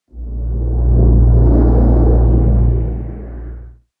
Minecraft Version Minecraft Version snapshot Latest Release | Latest Snapshot snapshot / assets / minecraft / sounds / ambient / cave / cave9.ogg Compare With Compare With Latest Release | Latest Snapshot
cave9.ogg